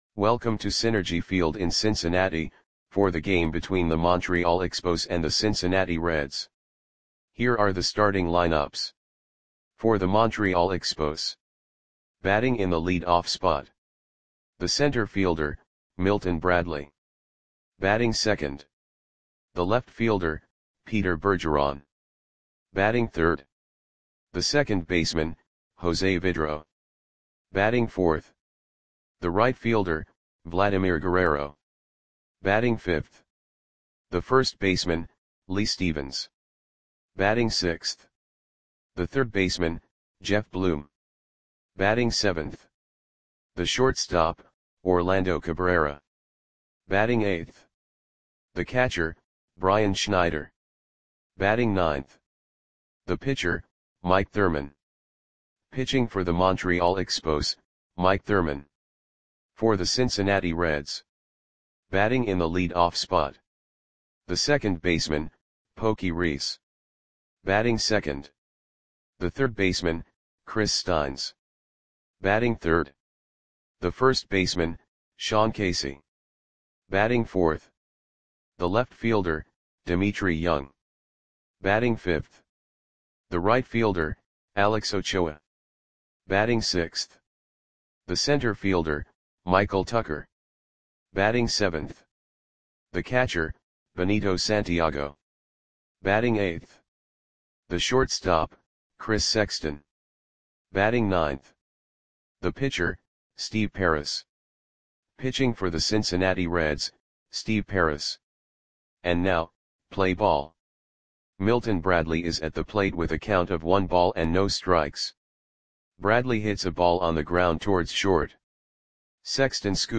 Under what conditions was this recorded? Lineups for the Cincinnati Reds versus Montreal Expos baseball game on September 3, 2000 at Cinergy Field (Cincinnati, OH).